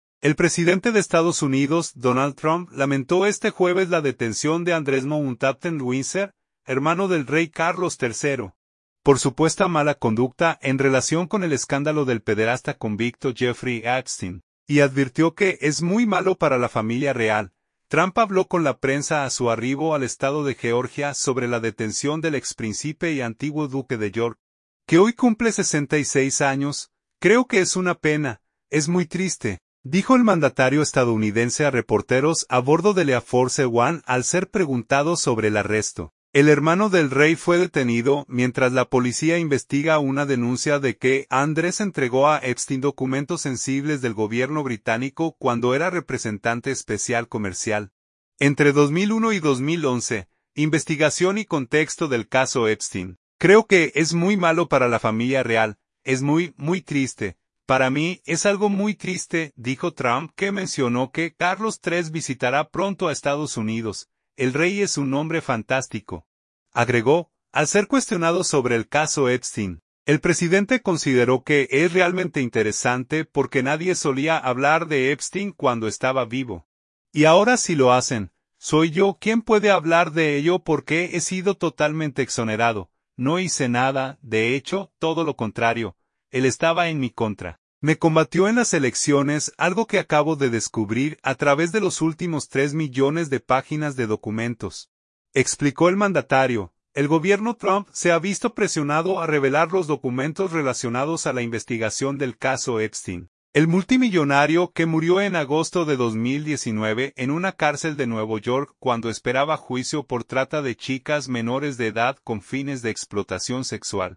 Trump habló con la prensa a su arribo al estado de Georgia sobre la detención del exprincípe y antiguo duque de York, que hoy cumple 66 años.
"Creo que es una pena. Es muy triste", dijo el mandatario estadounidense a reporteros a bordo del Air Force One al ser preguntado sobre el arresto.